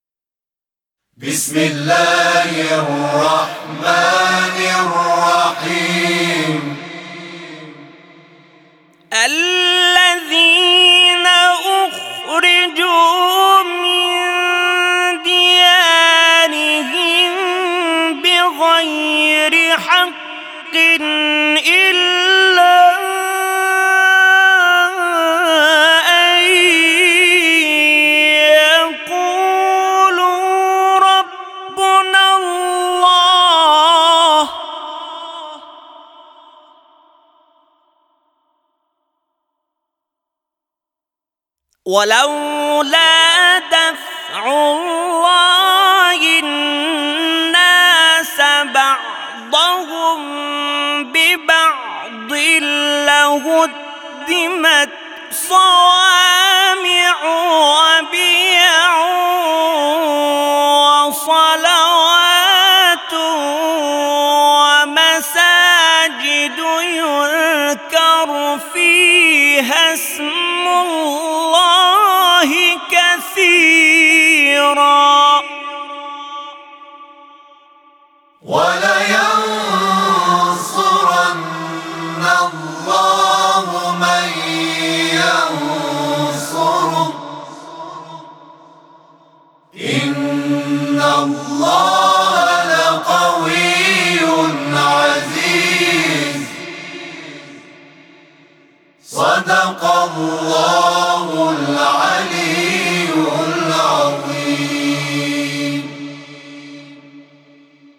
صوت همخوانی آیه 40 سوره حج از سوی گروه تواشیح «محمد رسول‌الله(ص)»